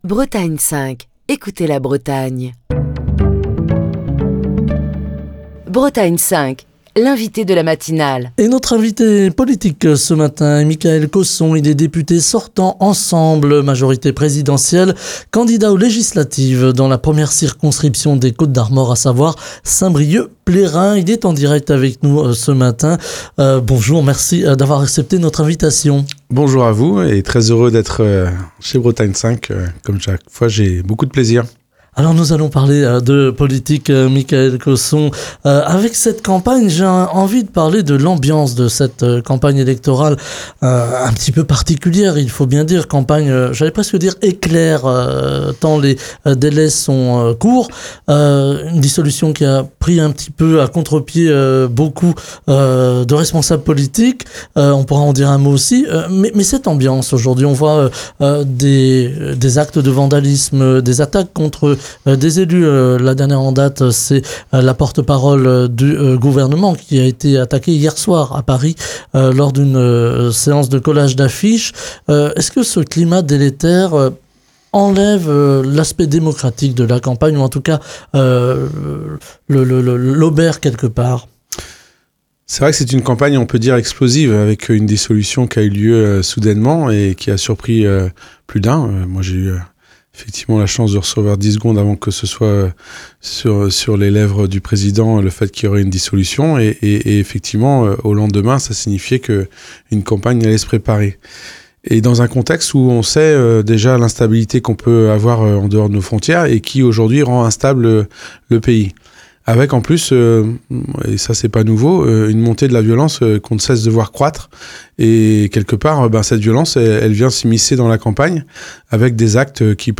Mickaël Cosson, député sortant de l'Ensemble ! (Majorité présidentielle) et candidat aux législatives dans la 1ère circonscription des Côtes d'Armor (Saint-Brieuc-Plérin), est l'invité de la matinale de Bretagne 5. À trois jours du deuxième tour des législatives anticipées, et alors que les altercations et actes d'intimidation envers les candidats se multiplient, Mickaël Cosson revient sur le climat de violence qui caractérise cette campagne électorale courte et atypique.